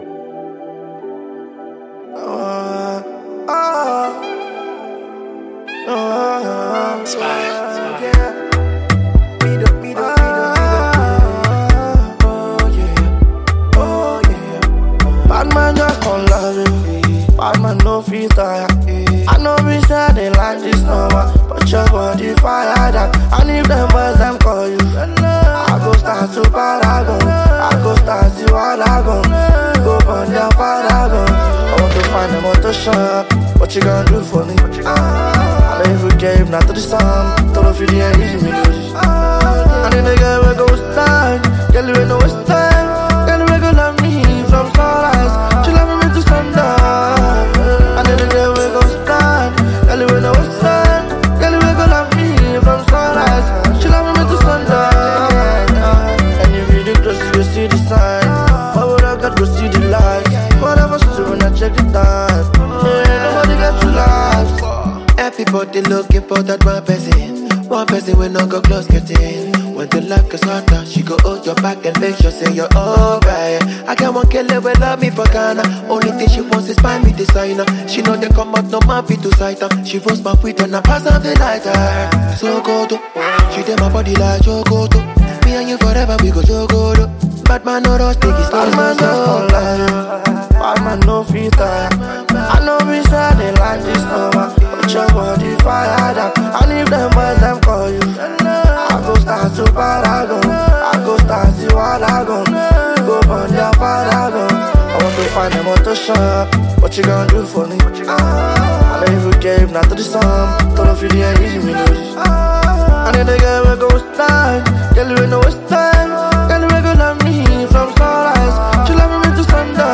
Nigerian fast rising singer and songwriter
Afropop/R&B